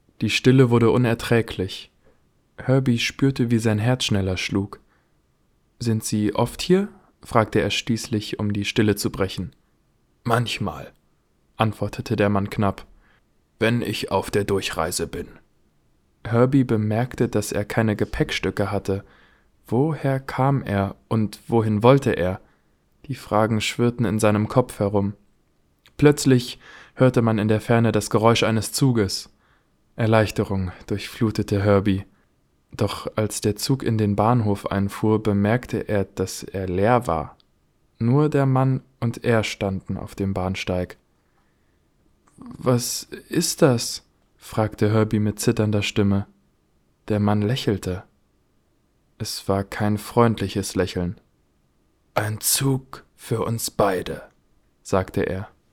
Deutscher Sprecher, Helle Stimme, Junge Stimme, Mittel alte Stimme, Schauspieler, Sänger, Werbesprecher, Off-Sprecher, Dokumentation, Geschichte, Buch
Sprechprobe: eLearning (Muttersprache):